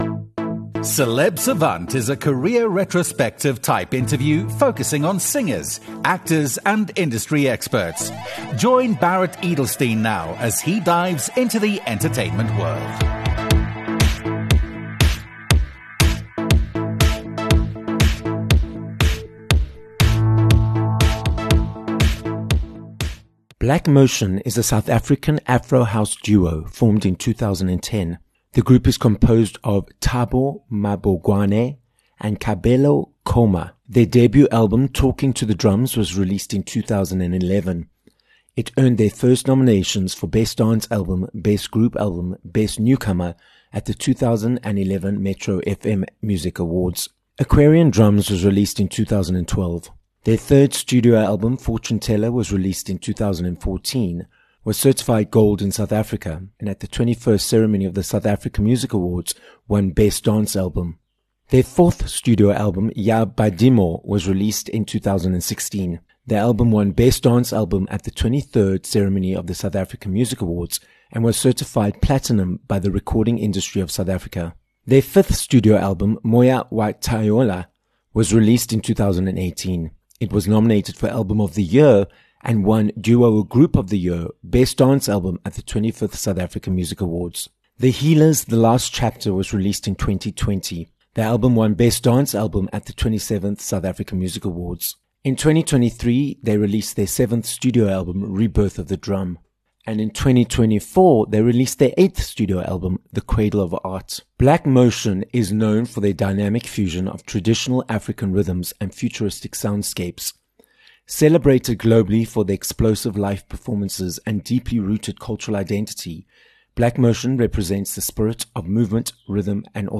Black Motion - Thabo Mabogwane and Kabelo Koma from the multi SAMA award-winning Afro house duo - joins us live in studio on this episode of Celeb Savant. We hear Thabo and Kabelo's individual stories, how they create music, how they prepare for their sets, and more about their events - Friends of Black Motion. This episode of Celeb Savant was recorded live in studio at Solid Gold Podcasts, Johannesburg, South Africa.